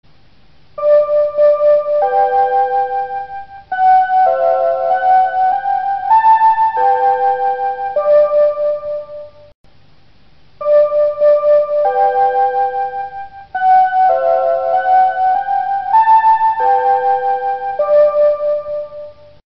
Звук радiо СРСР